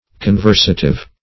Search Result for " conversative" : The Collaborative International Dictionary of English v.0.48: Conversative \Con*ver"sa*tive\ (k[o^]n*v[~e]r"s[.a]*t[i^]v), a. Relating to intercourse with men; social; -- opposed to contemplative.